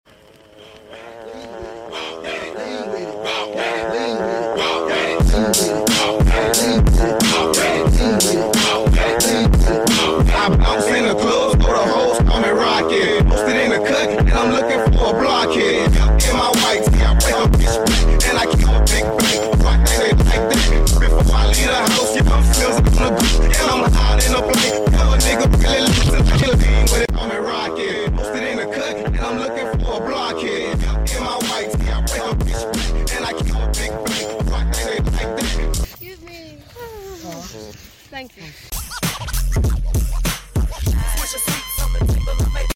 drift on shopping trolley sound effects free download